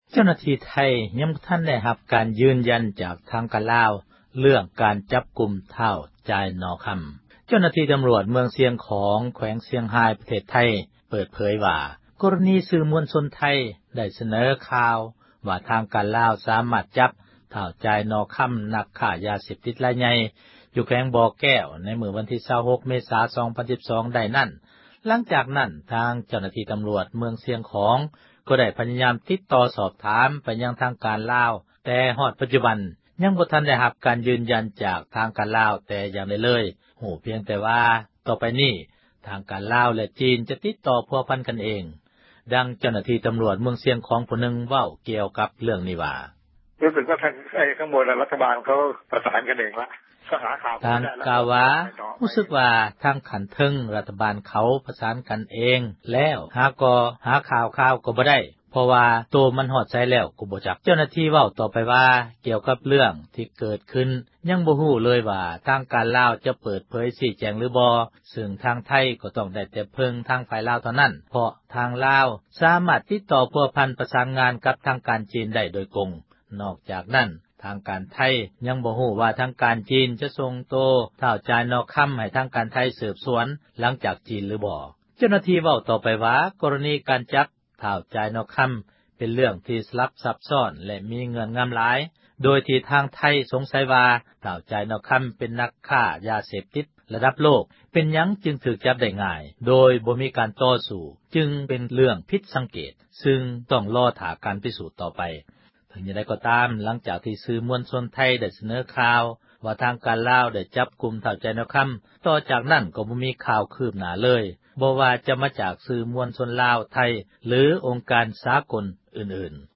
ດັ່ງເຈົ້າໜ້າທີ່ ຕໍາຣວດ ເມືອງຊຽງຂອງ ເວົ້າກ່ຽວກັບ ເຣື່ອງນີ້ວ່າ: